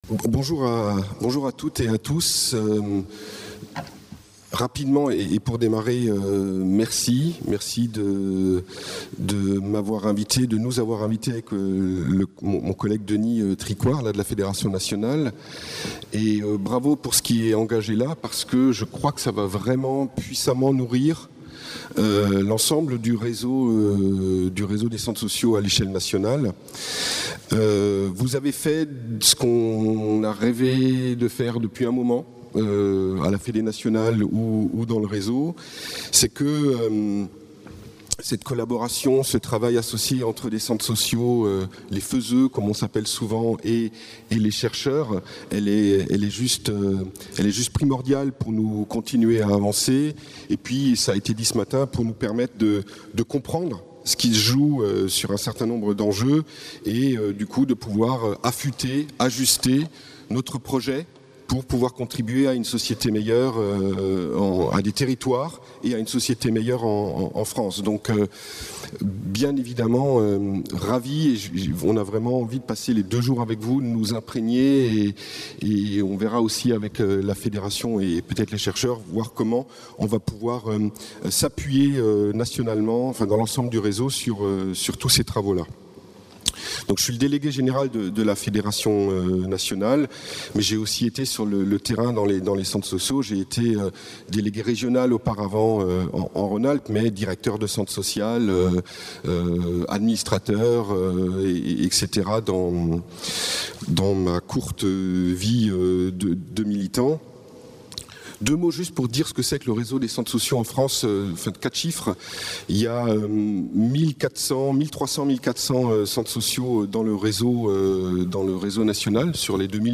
(Table ronde 1)